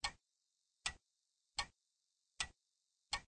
cf_clock_ticking.ogg